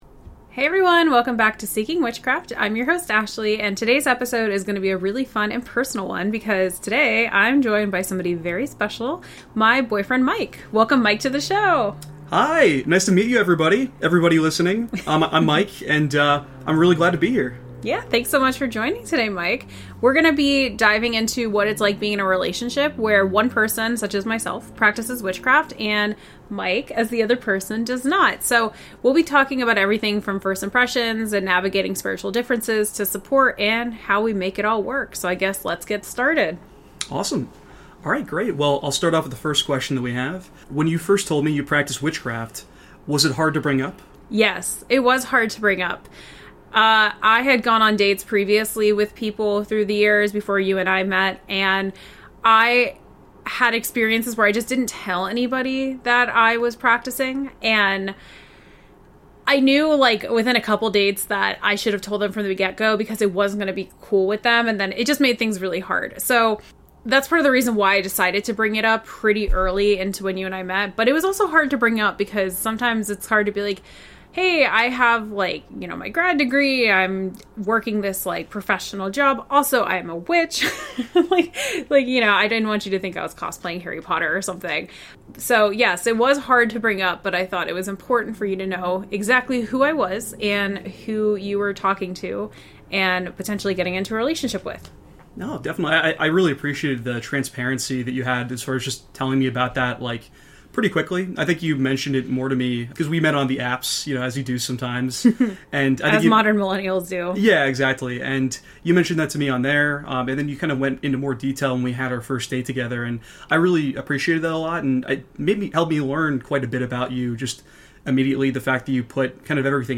Together, they discuss how they balance differences in belief, what it means to support each other’s spiritual paths, and the importance of curiosity, communication, and respect. Whether you’re a witch dating a non-practitioner or simply interested in how couples bridge spiritual differences, this honest conversation offers insights, laughs, and plenty of heart.